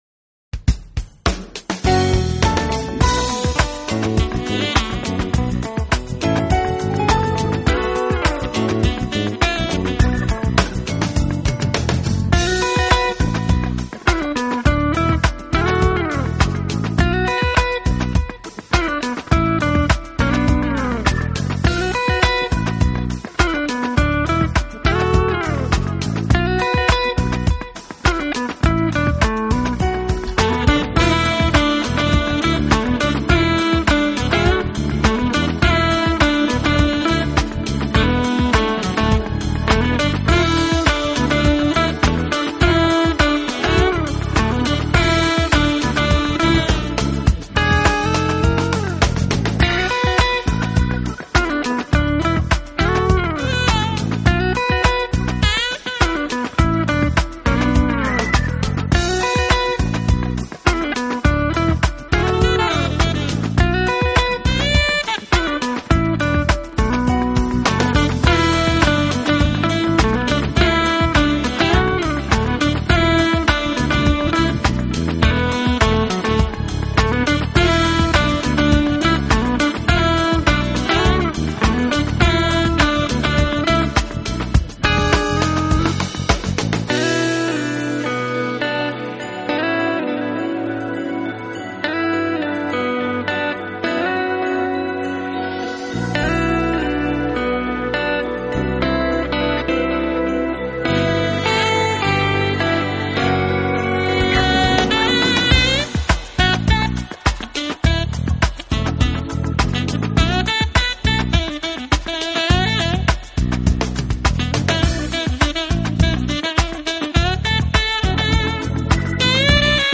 Genre: Smooth Jazz
guitars
keyboards, bass, synthesizer
drums
saxophone
percussion
vocals